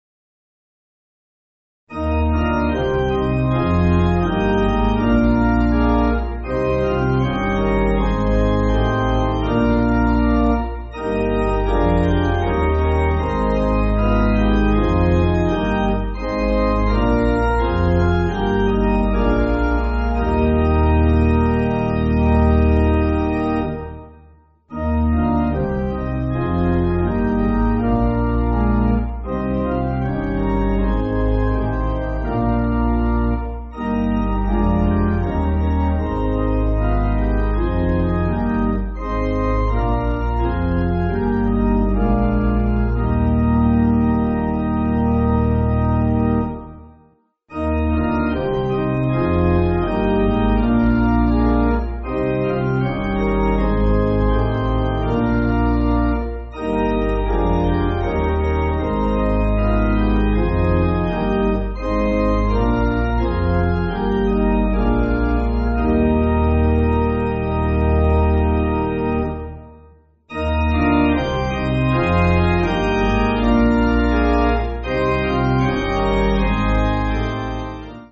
(CM)   4/Eb